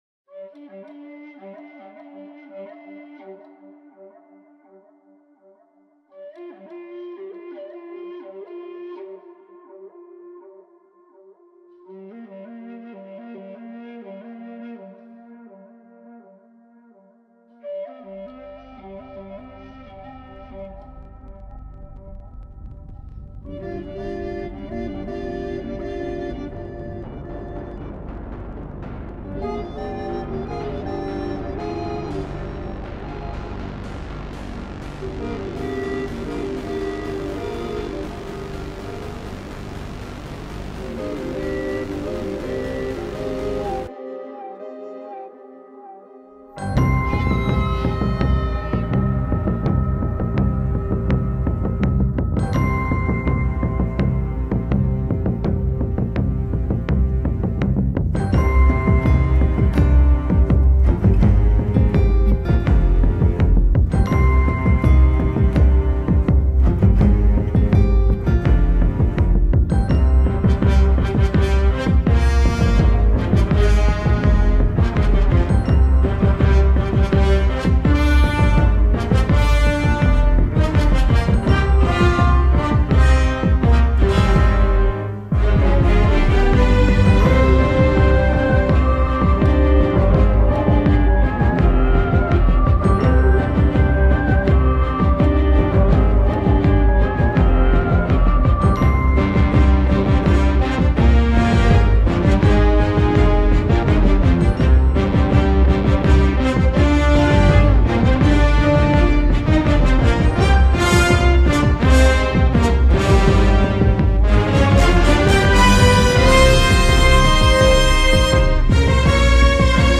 for orchestra to rent.